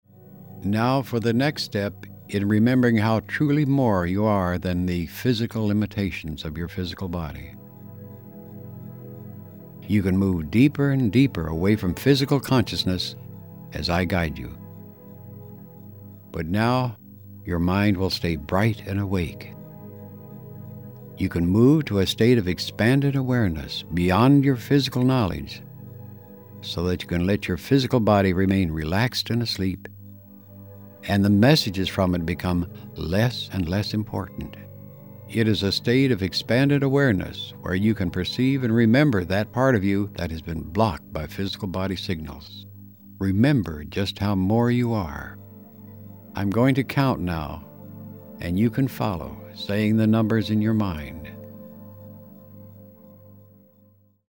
Ukázka z CD Ukázka ze série CD Going Home® Subject (Cesta domů Subjekt 7 CD).mp3 (2,07 MB) Parametry zboží Kategorie výrobku: Cílená série CD s technologií Hemi-Sync®, navržená pro konkrétní využití. Celý název: Going Home® Subject 7 CD (Cesta domů Subjekt 7 CD) Verbální vedení: Anglické verbální vedení.